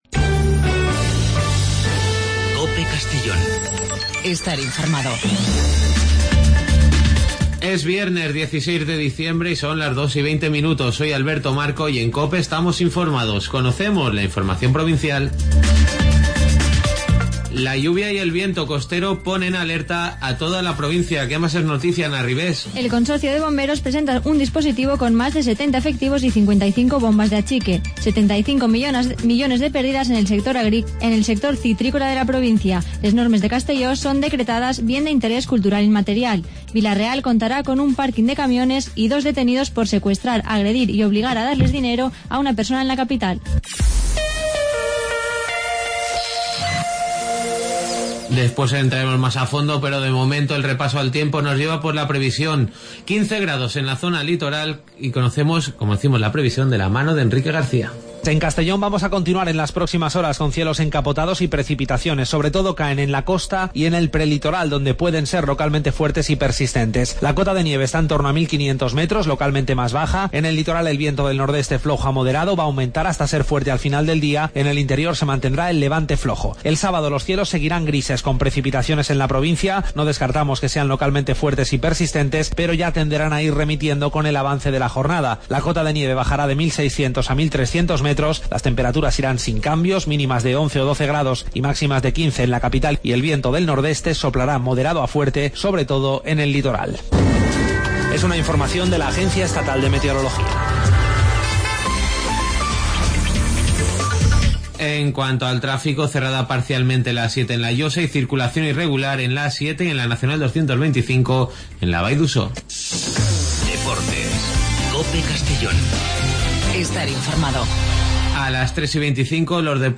AUDIO: Las noticias del día de 14:20 a 14:30 en Informativo Mediodía COPE en Castellón.